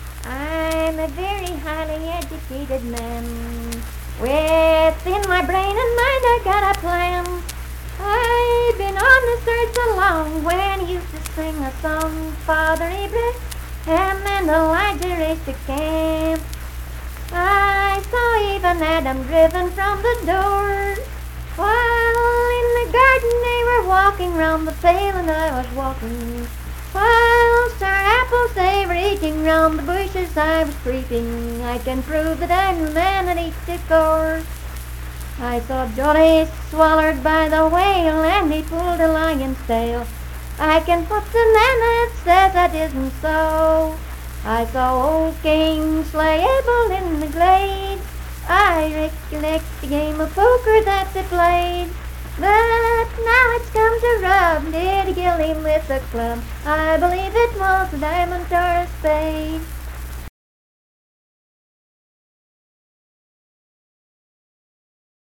Unaccompanied vocal music
Verse-refrain 1(14). Performed in Strange Creek, Braxton, WV.
Voice (sung)